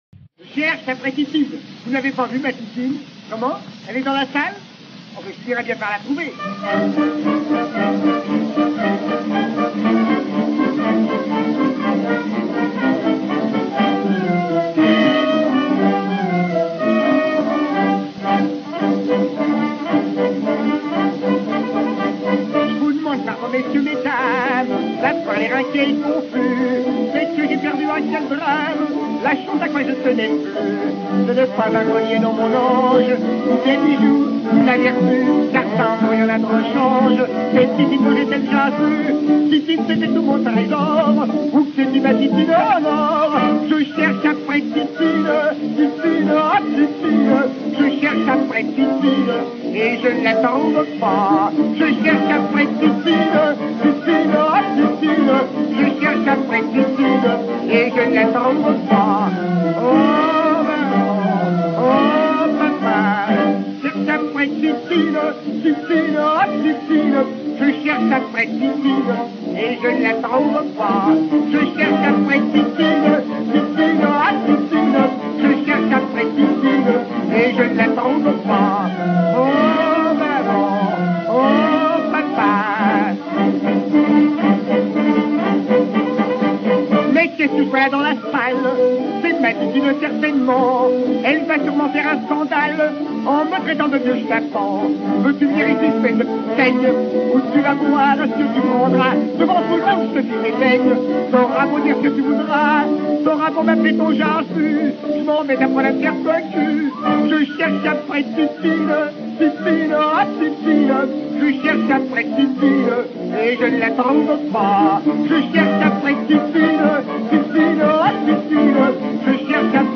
chanson humoristique